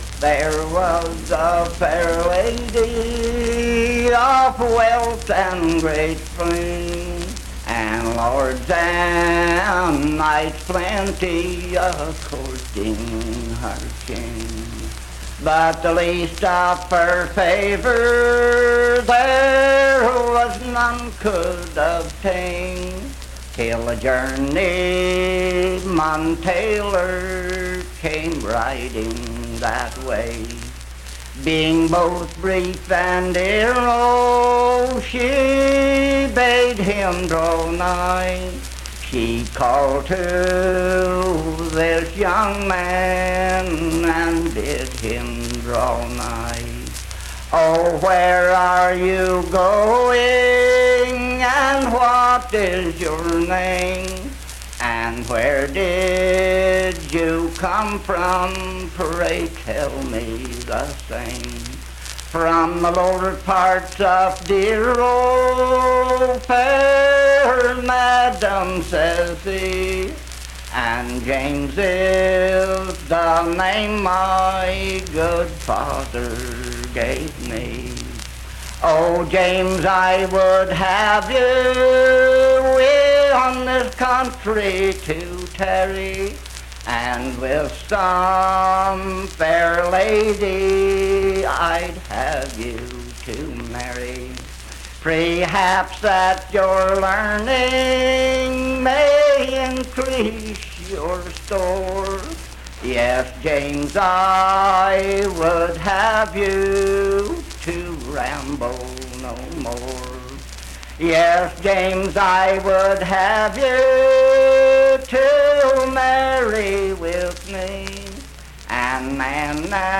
Unaccompanied vocal music performance
Voice (sung)
Roane County (W. Va.), Spencer (W. Va.)